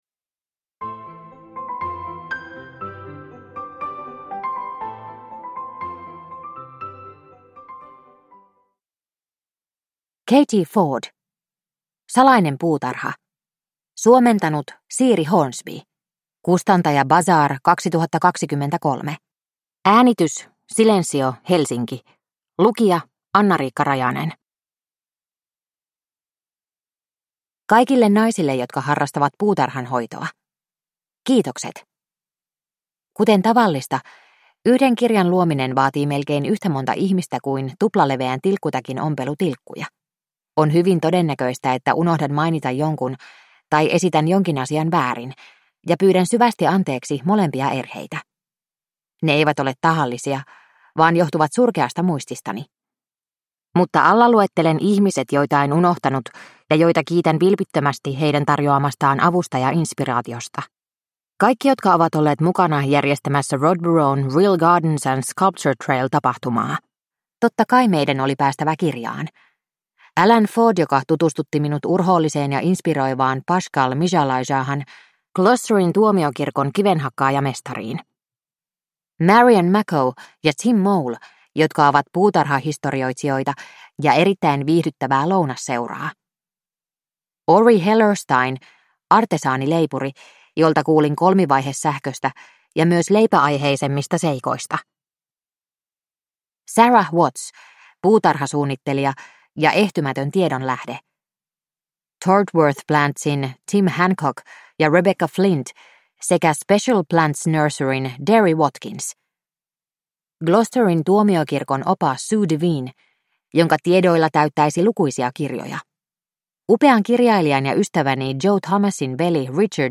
Salainen puutarha – Ljudbok – Laddas ner